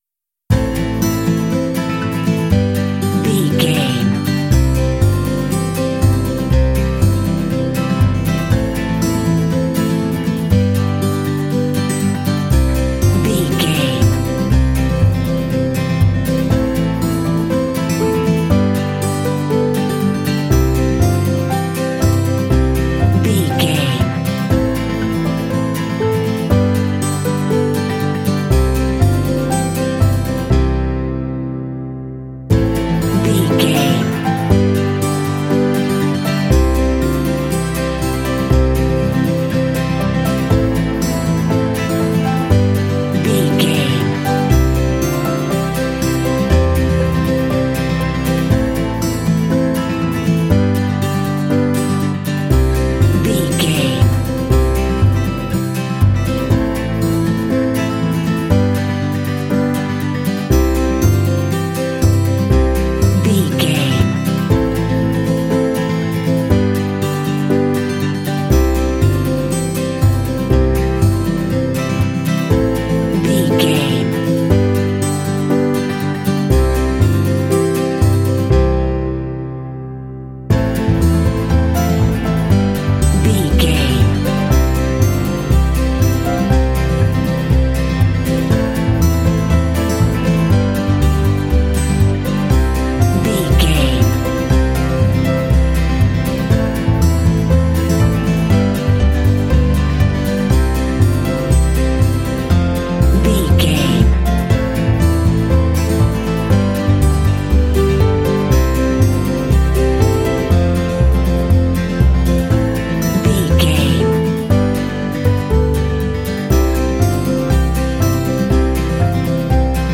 Ionian/Major
inspirational
hopeful
soothing
acoustic guitar
bass guitar
piano
strings